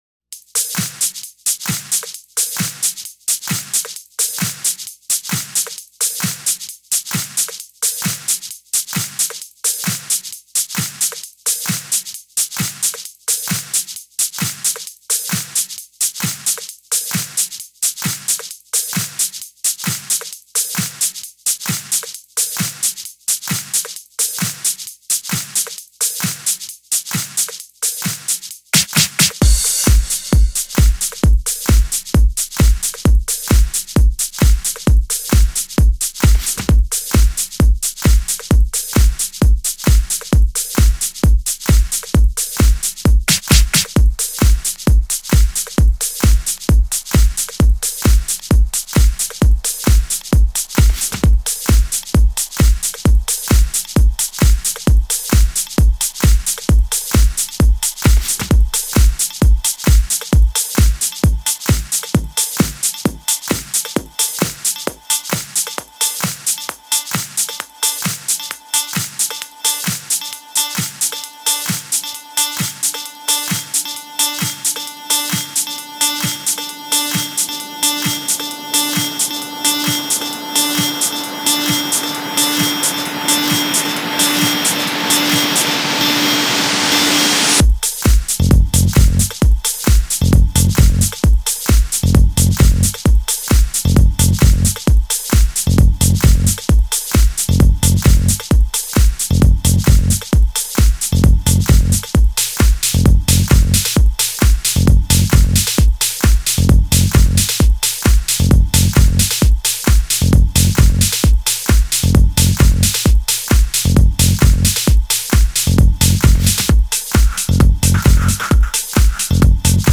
Транс музыка